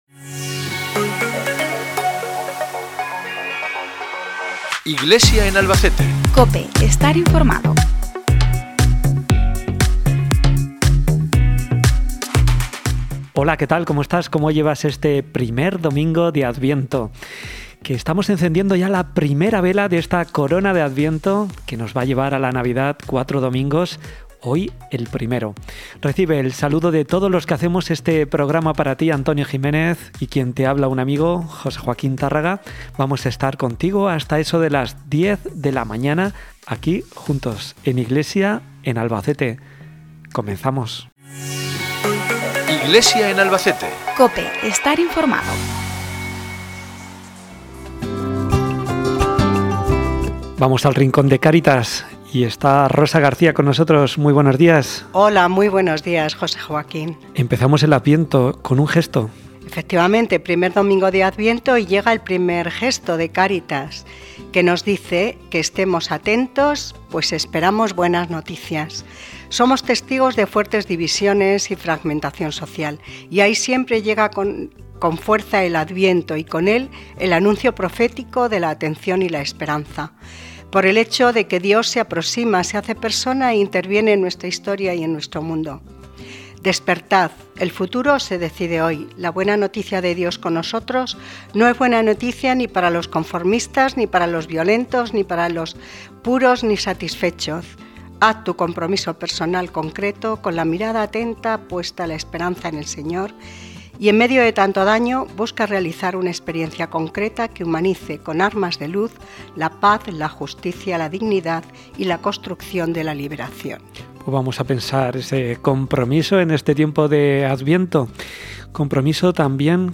Mesa Redonda.
En el I Encuentro Diocesano de Familias en el Seminario de Albacete.